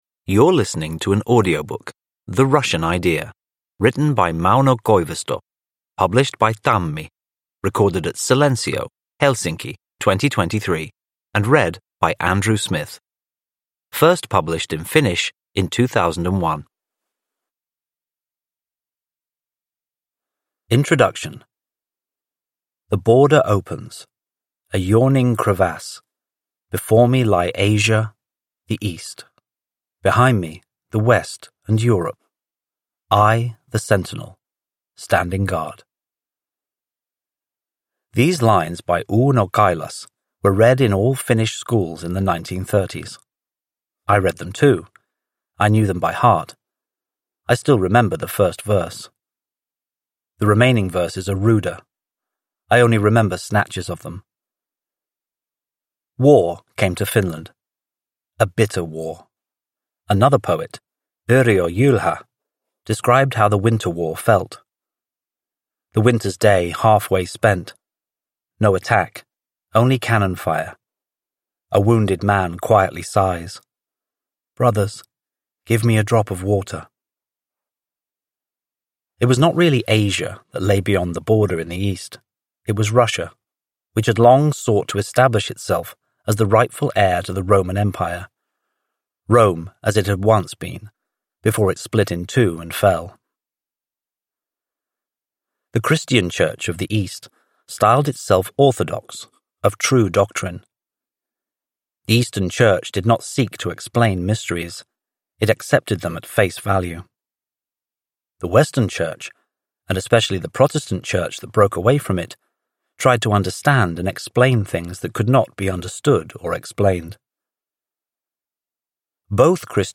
The Russian Idea – Ljudbok – Laddas ner